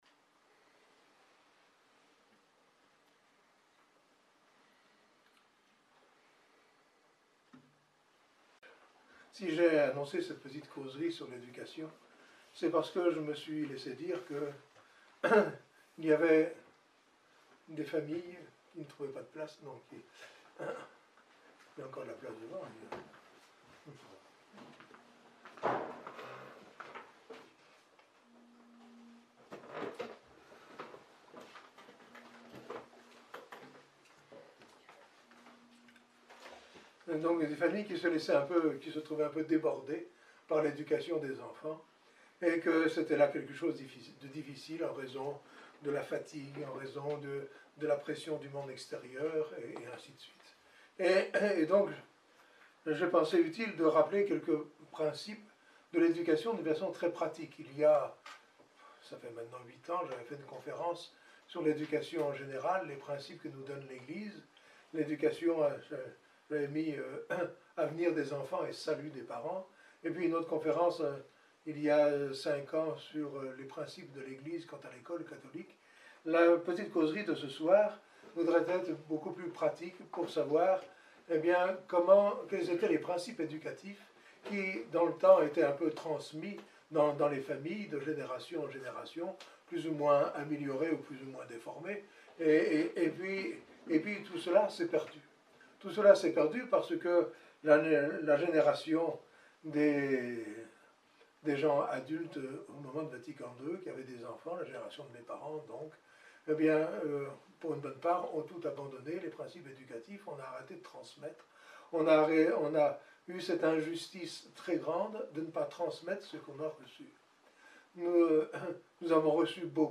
Causerie : En matière d’éducation, suffit-il de sauver les meubles ?